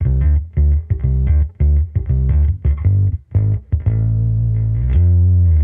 Index of /musicradar/sampled-funk-soul-samples/85bpm/Bass
SSF_PBassProc2_85D.wav